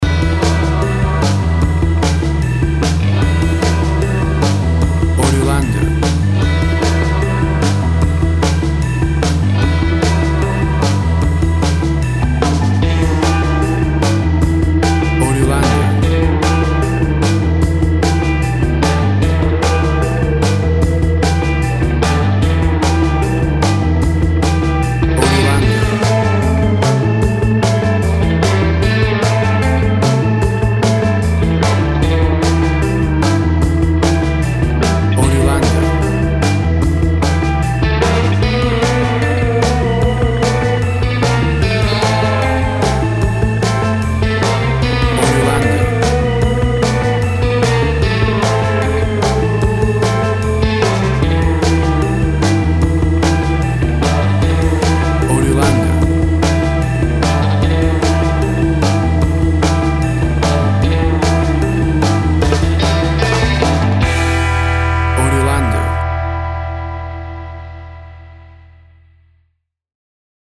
WAV Sample Rate 16-Bit Stereo, 44.1 kHz
Tempo (BPM) 150